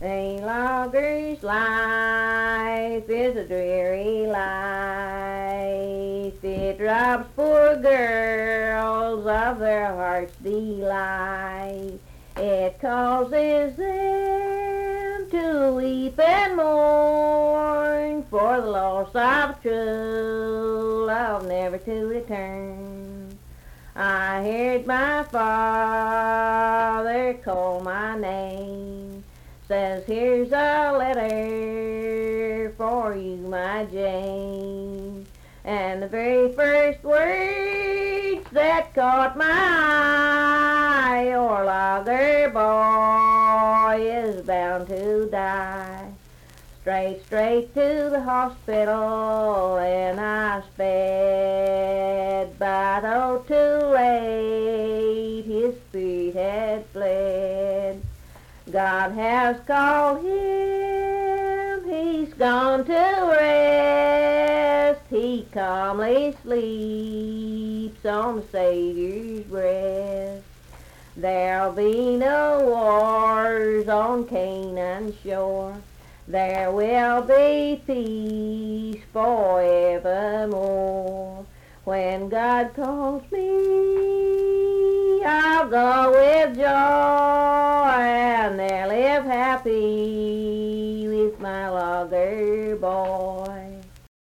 Logger's Life - West Virginia Folk Music | WVU Libraries
Unaccompanied vocal music
Voice (sung)